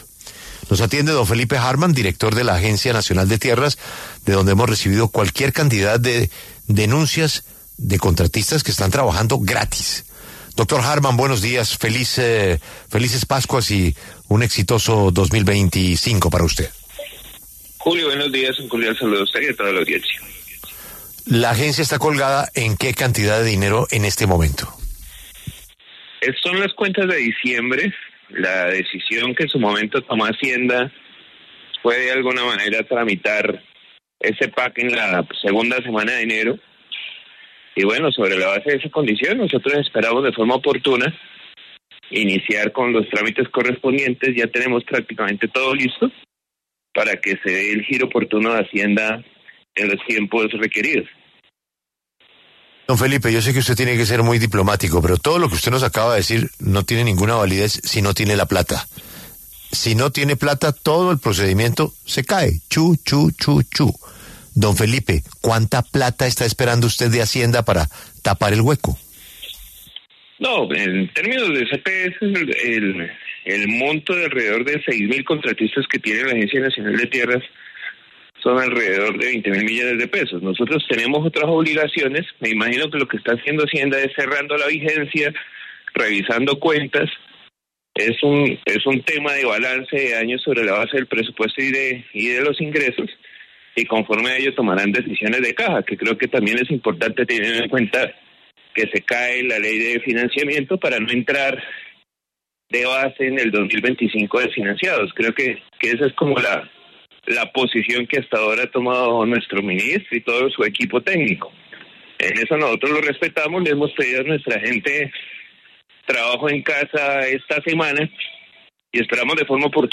Felipe Harman, director de la Agencia Nacional de Tierras, conversó en los micrófonos de La W sobre el no pago a funcionarios de su entidad, lo cual, según algunas fuentes, se debe a que desde el Ministerio de Hacienda no se han hecho los giros correspondientes para sacar adelante las nóminas de diferentes entidades.